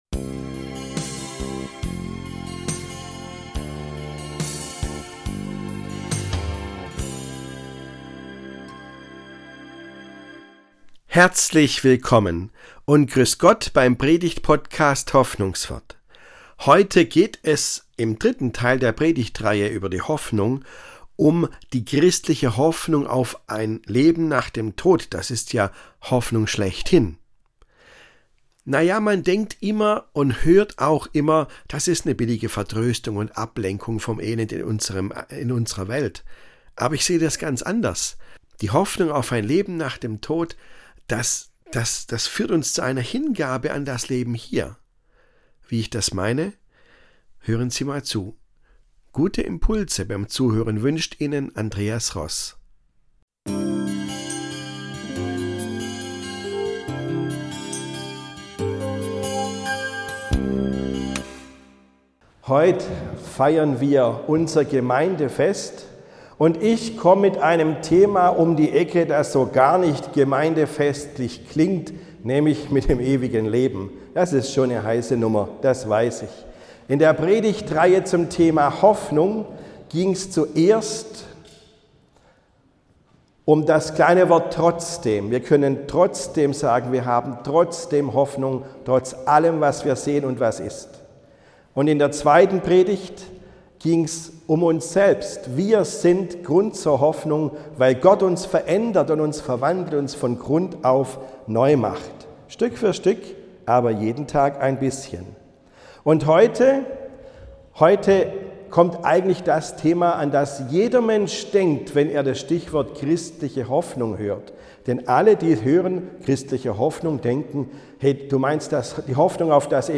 Dritter und letzter Teil der Predigtreihe „Hoffnung ist Kraft“. Übrigens, die veränderte Akustik liegt diesmal daran, dass der Gottesdienst in einer Sporthalle stattgefunden hat.